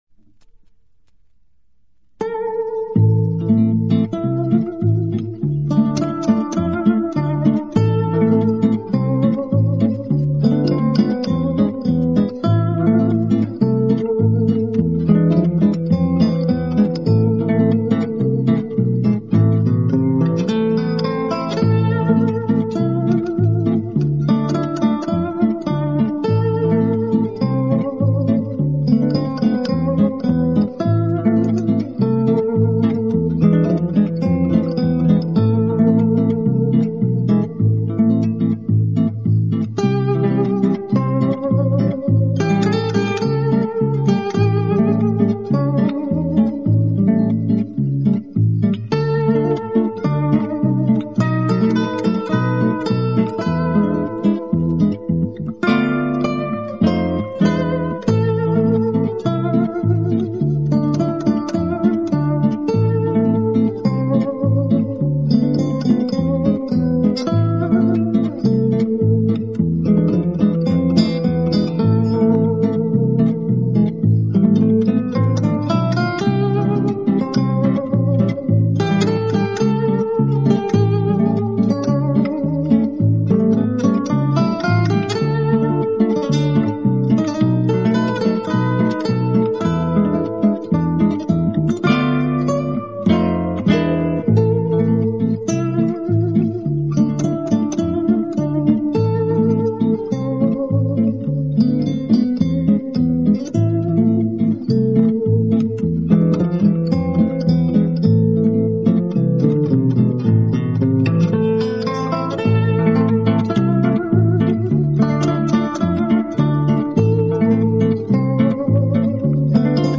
Genero/Genre: Instrumental/guitar